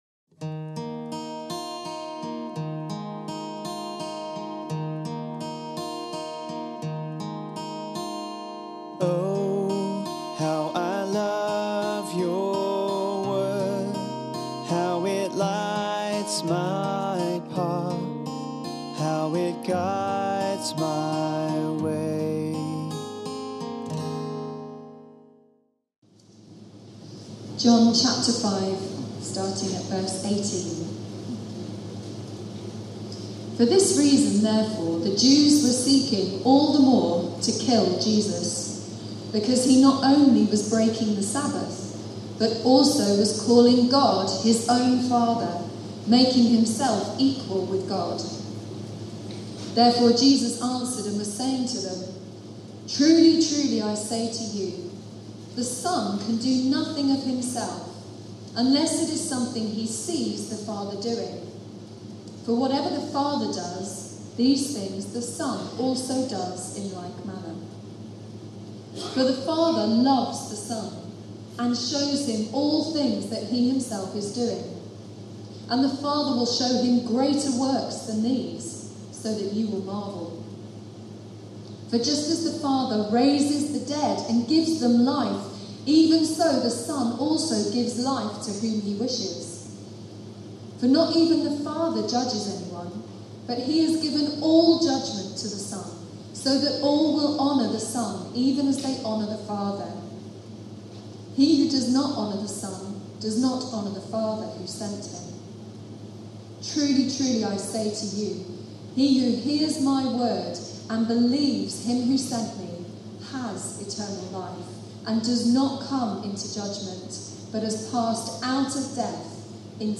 (With apologies for the poor recording quality)